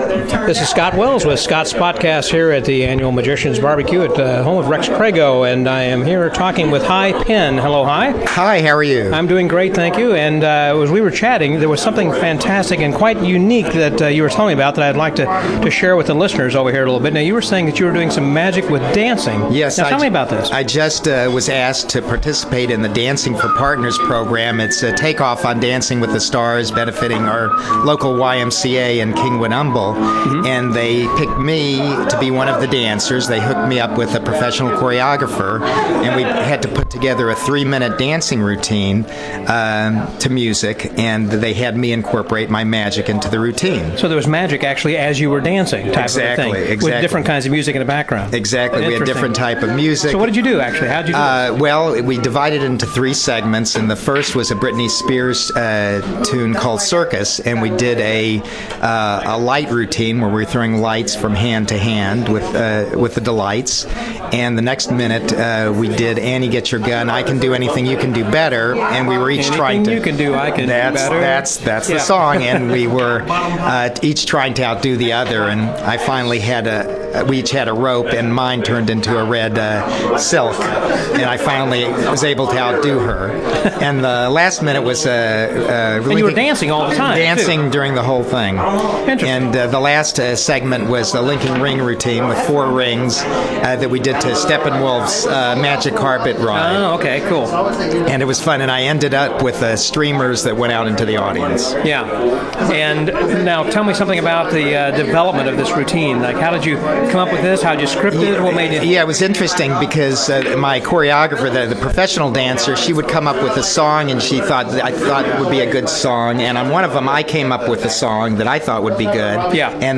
Dancing with the Magic Stars, an interview